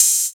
MZ Open Hat [TM88].wav